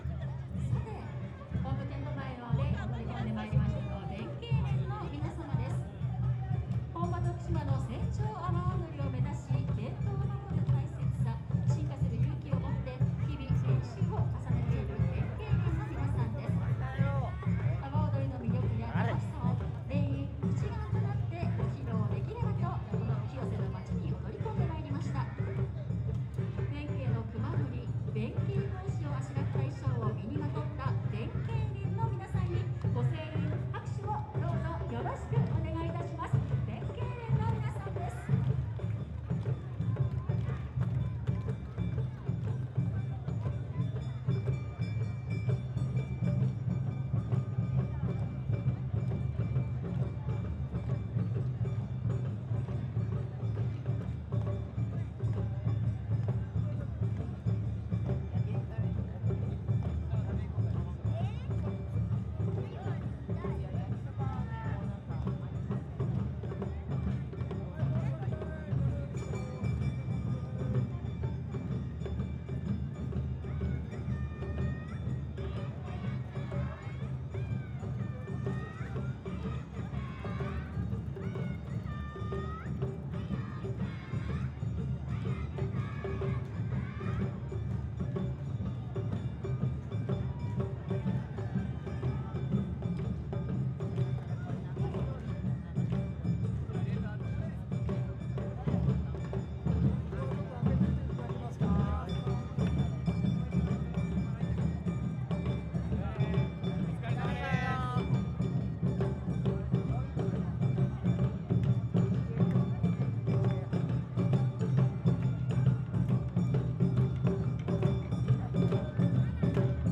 清瀬市「ふれあいロード夏まつり」　第20回清瀬南口阿波おどり大会
マイクは オーディオテクニカ BP4025
ローカットは FLAT
HPF OFF